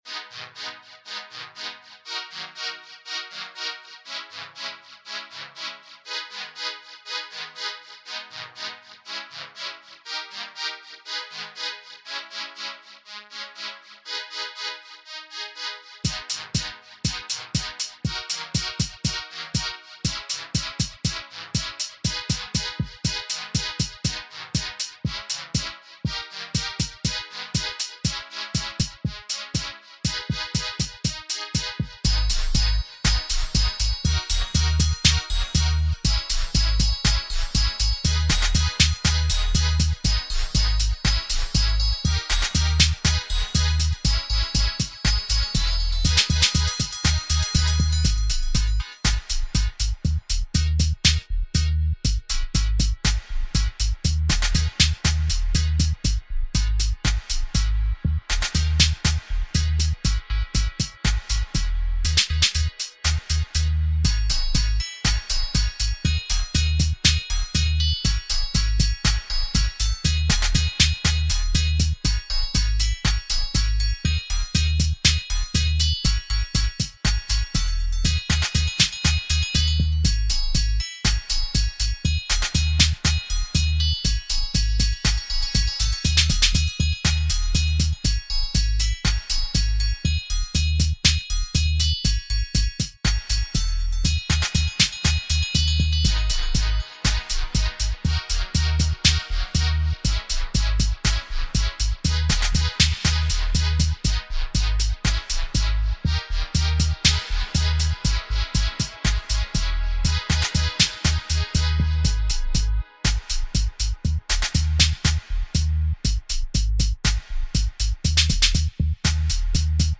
conscious dub